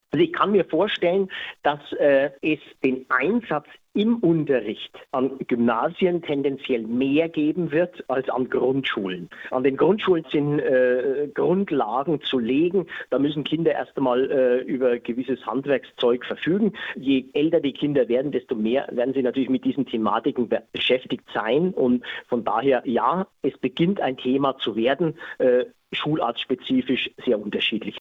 Interview: Künstliche Intelligenz an Schulen - PRIMATON